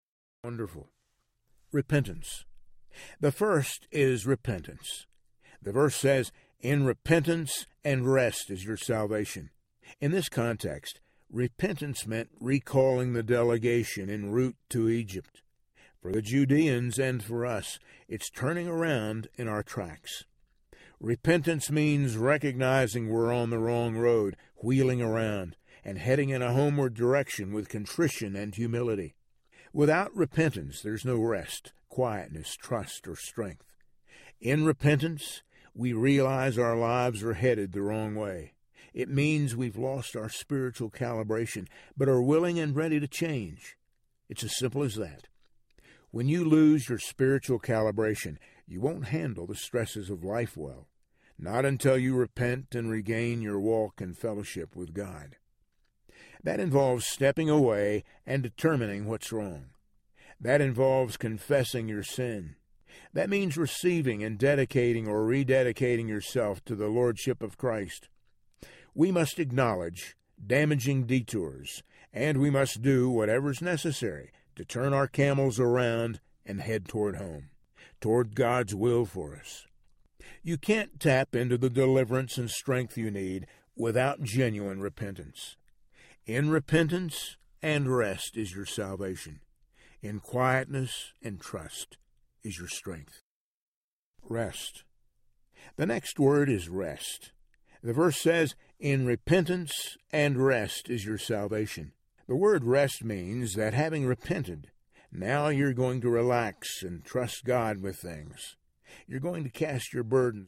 The Strength You Need Audiobook
Narrator
7.02 Hrs. – Unabridged